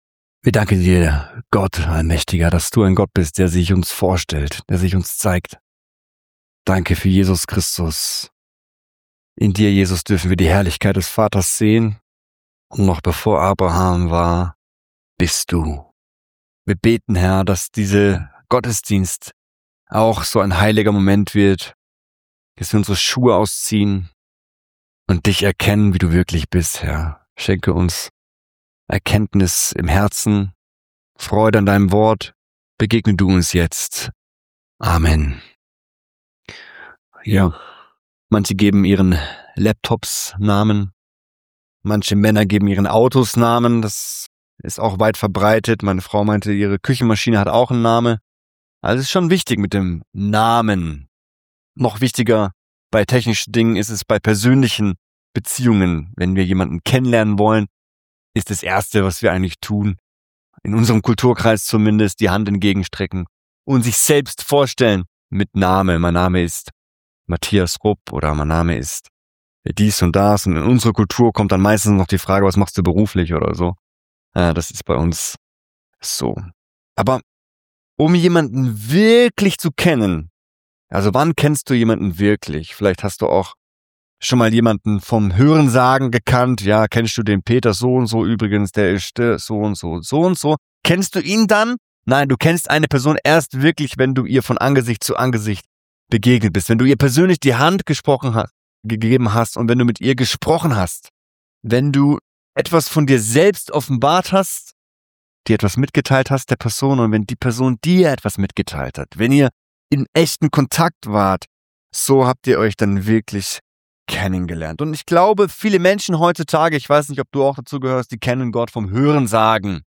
Predigtreihe Exodus - Part 2